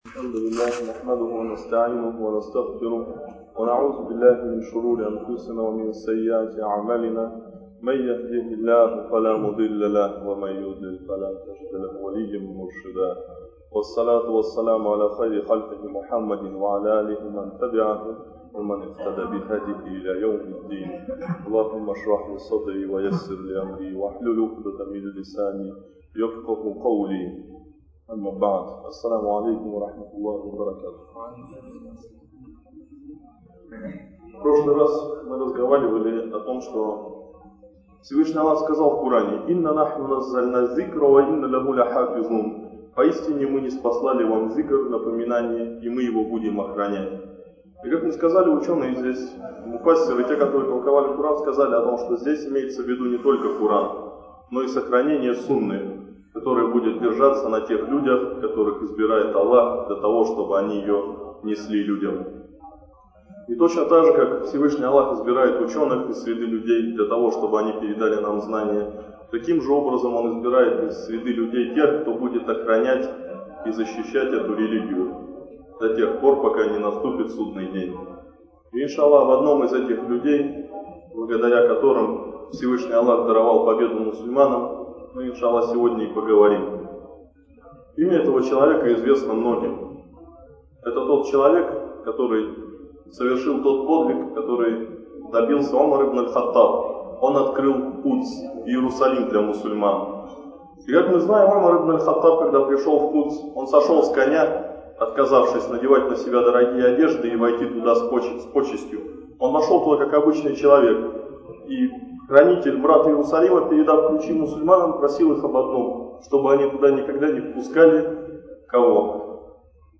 Лекция о особенностях упования на Аллаха. Это очень важная тема в разделе вероубеждения.